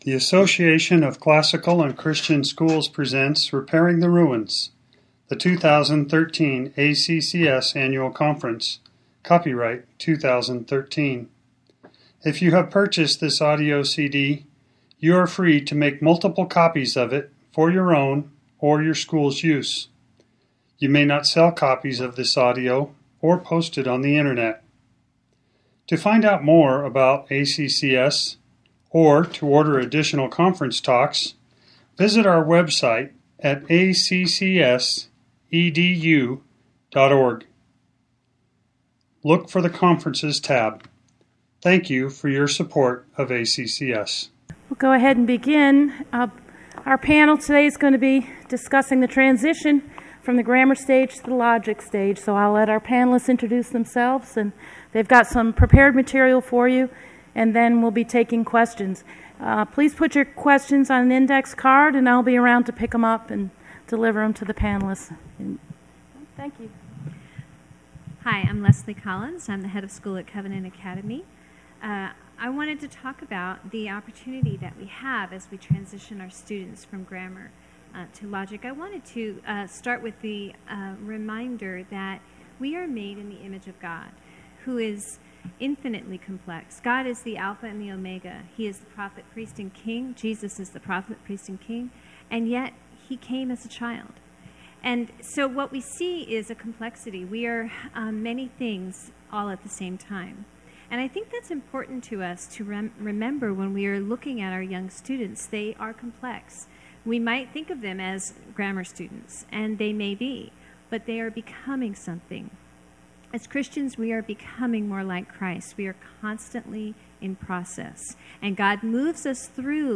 2013 Workshop Talk | 0:58:33 | 7-12, K-6
The Association of Classical & Christian Schools presents Repairing the Ruins, the ACCS annual conference, copyright ACCS.